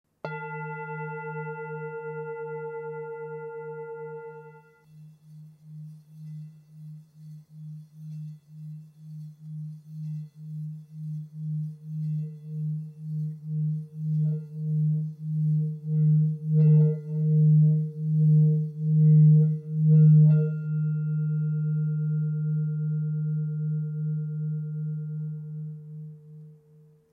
Tepaná tibetská mísa Nagpa o hmotnosti 1 787 g. Mísa je včetně paličky.
Způsob provedení mísy Tepaná
tibetska_misa_v12.mp3